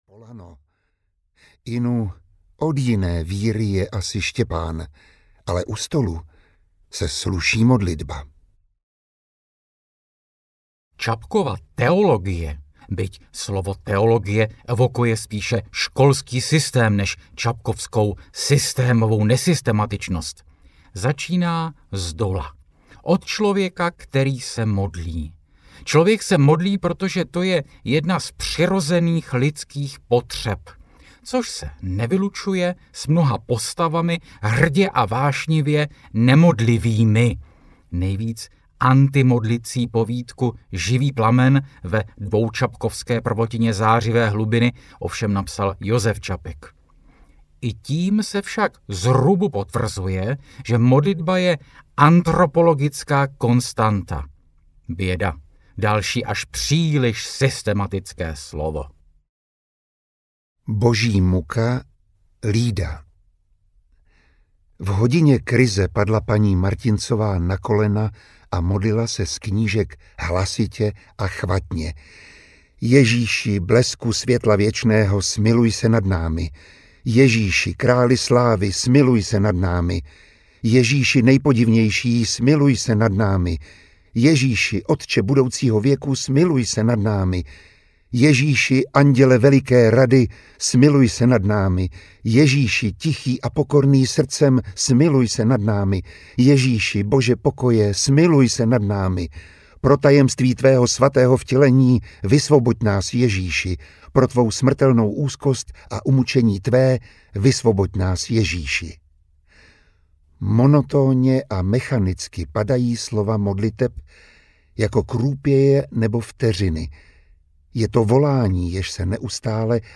Skoro modlitby audiokniha
Ukázka z knihy
• InterpretMiroslav Táborský, Martin C. Putna